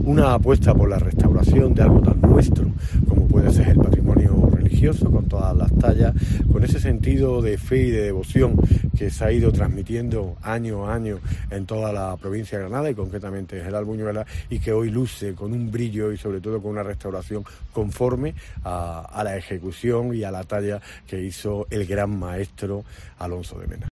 Antonio Granados, Delegado del Gobierno andaluz